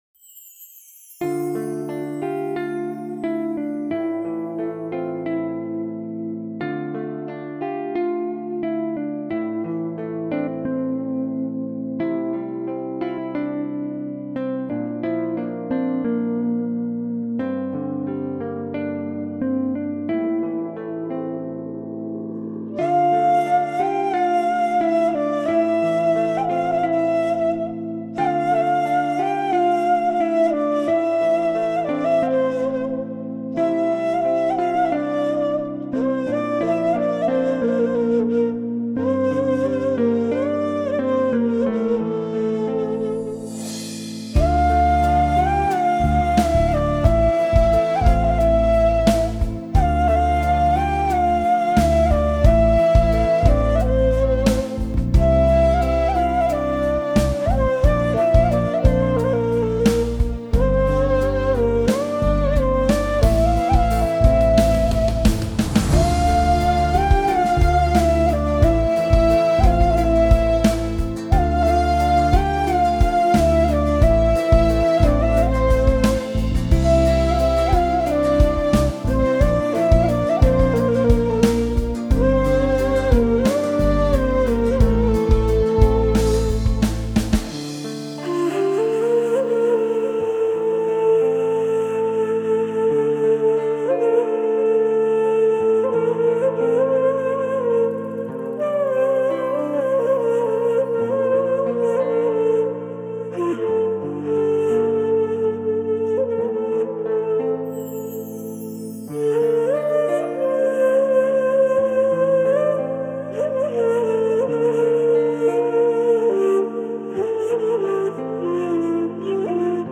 موسیقی بی کلام ملل